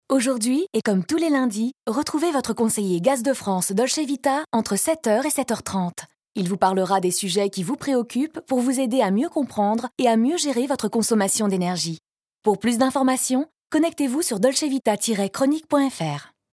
Calme
Chaleureux
Publicité radio
Rassurant
Voix off